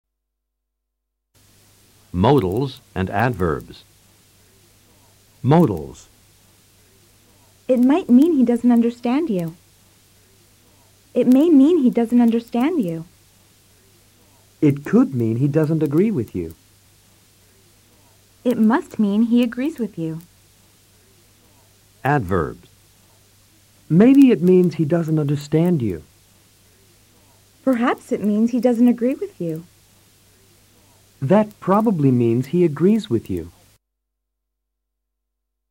Escucha atentamente a los profesores en estas oraciones.